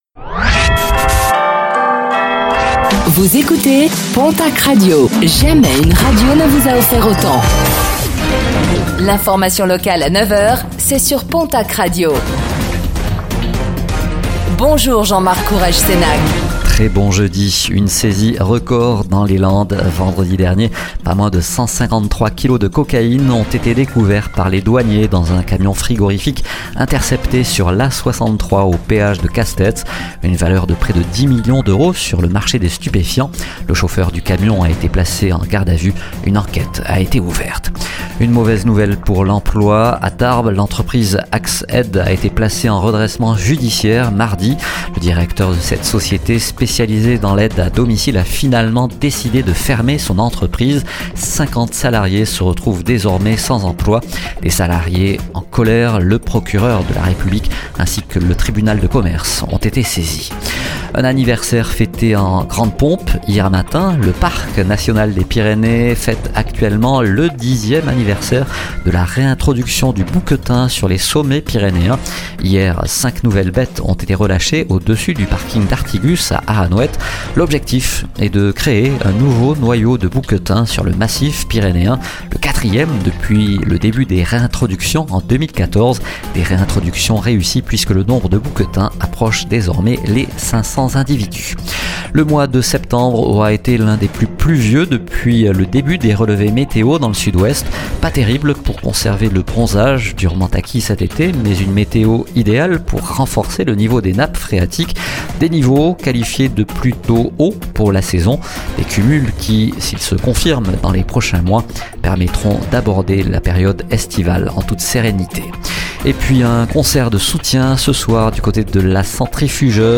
Infos | Jeudi 03 octobre 2024